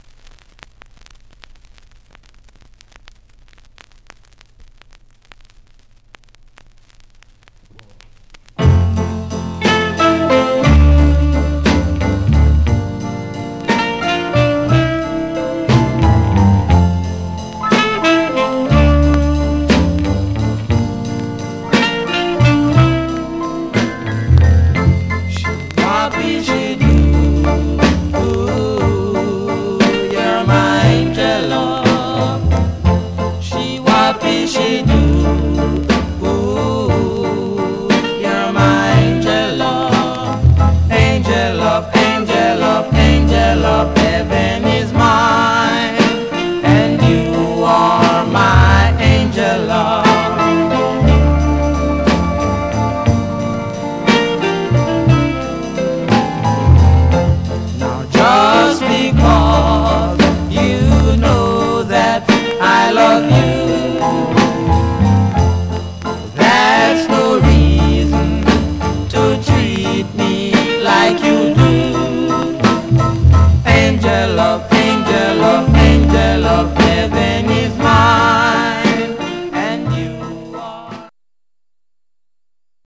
Surface noise/wear
Mono
R&b
Male Black Groups